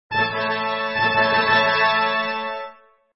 coin.mp3